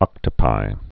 (ŏktə-pī)